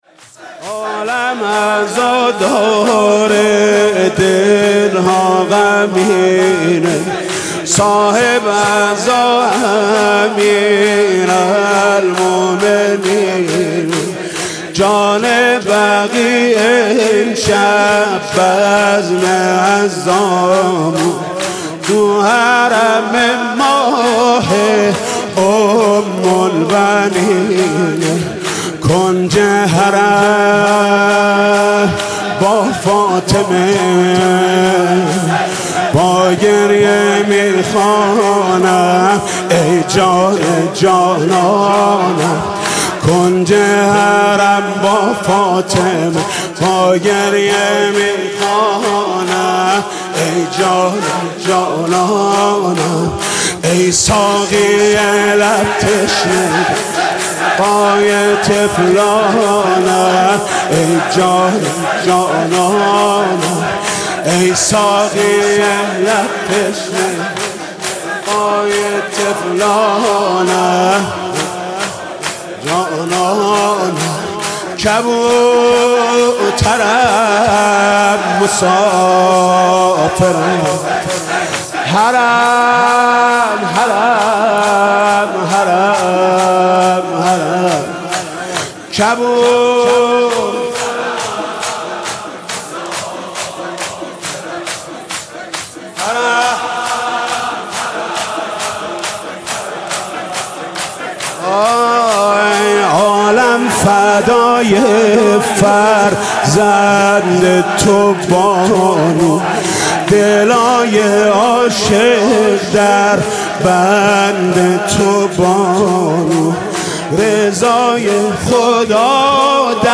شور: عالم عزا داره